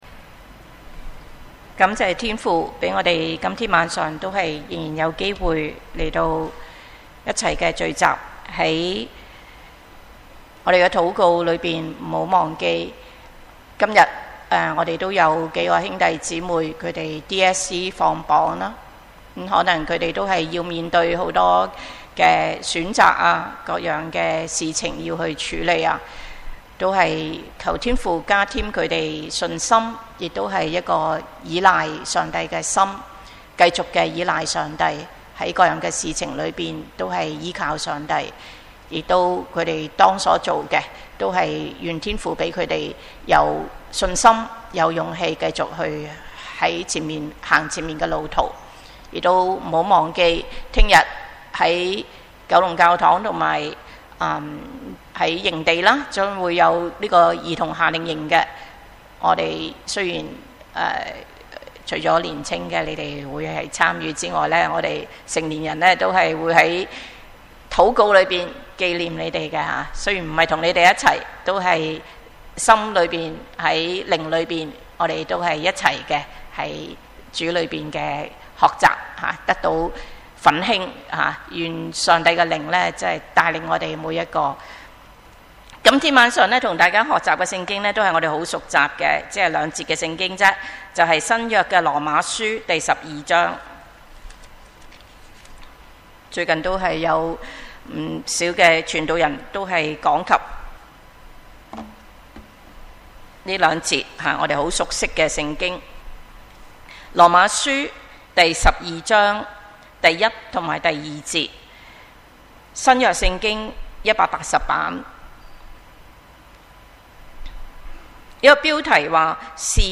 Wednesday Service